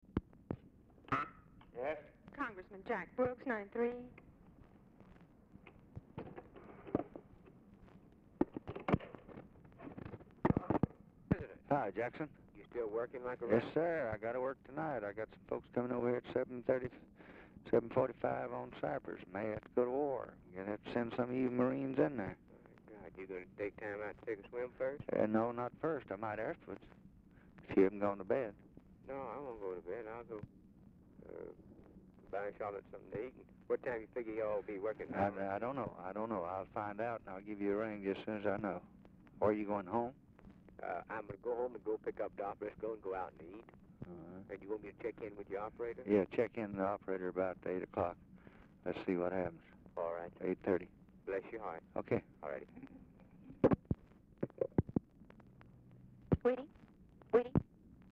Oval Office or unknown location
Telephone conversation
Dictation belt